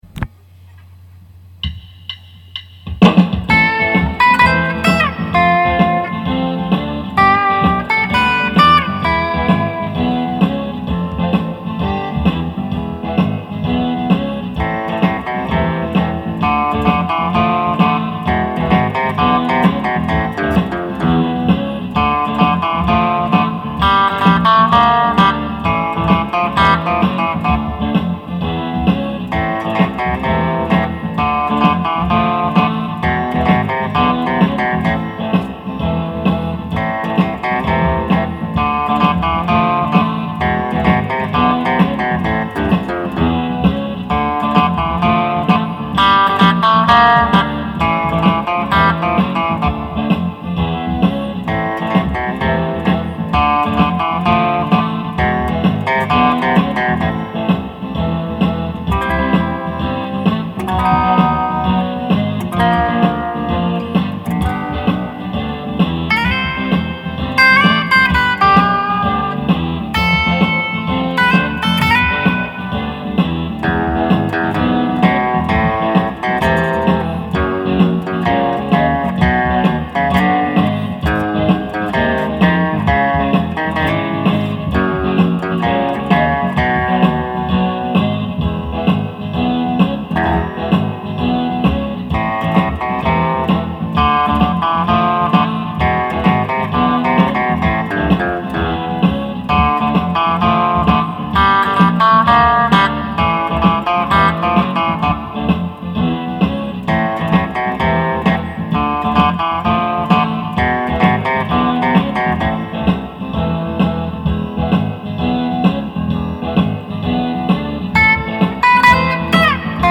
(Western)